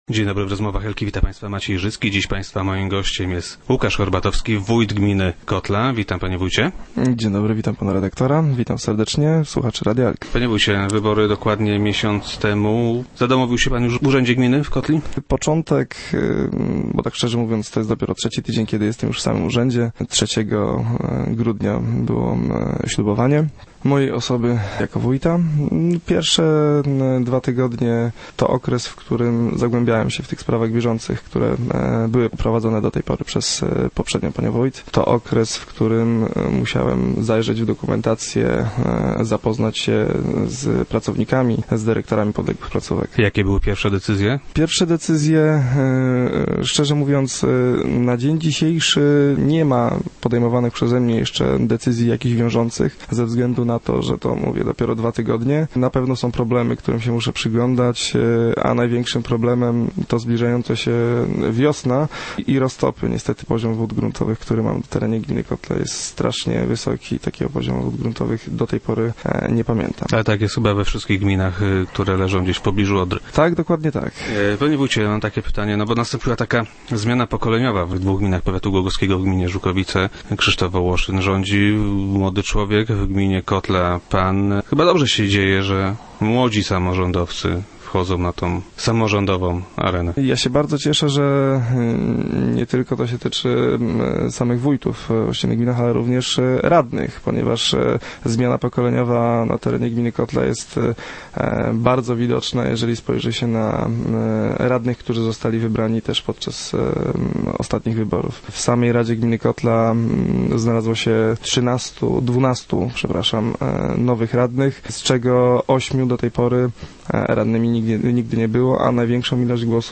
Wójt Horbatowski był gościem Rozmów Elki.
- Ostatnie tygodnie to okres w którym zagłębiałem się w sprawy bieżące gminy. Musiałem zajrzeć w dokumentację, zapoznać się z pracownikami i dyrektorami podległych placówek. Pierwsze decyzję ciągle przede mną. Na pewno są problemy, którym muszę się przyglądać - powiedział w radiowym studio Łukasz Horbatowski.